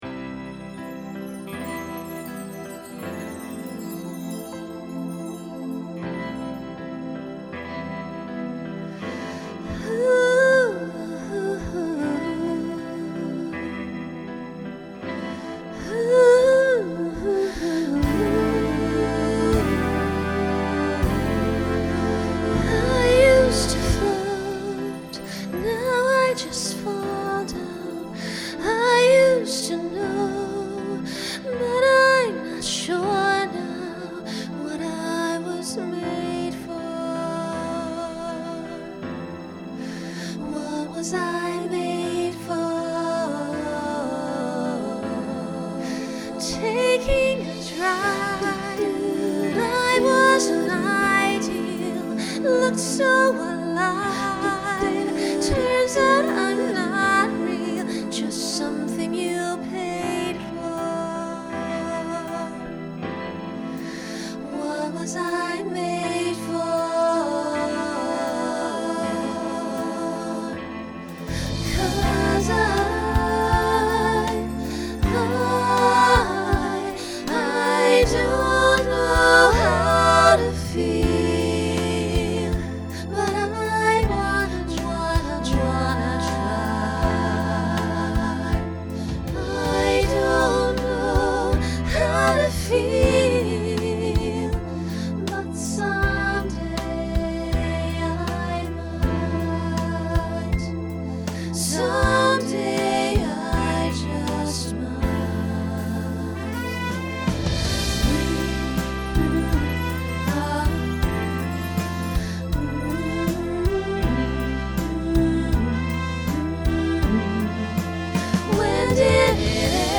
New SATB voicing for 2025.
Pop/Dance
Ballad